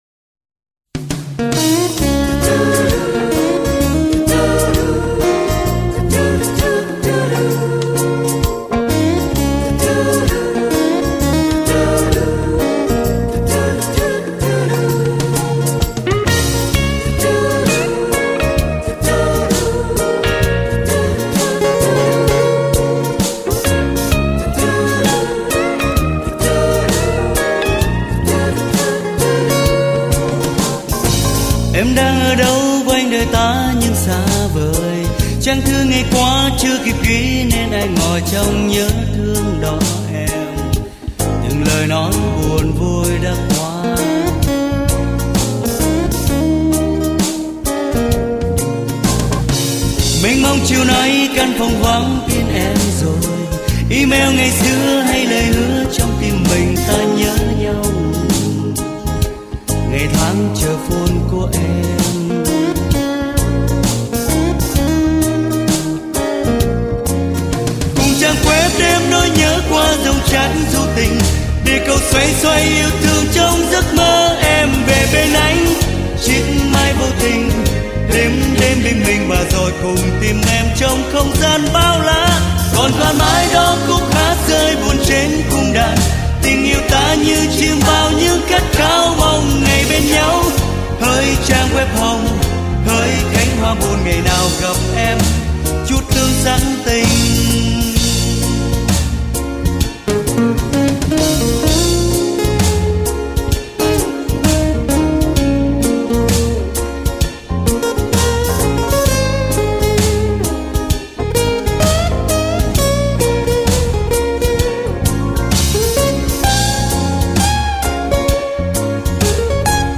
* Thể loại: Nhạc Việt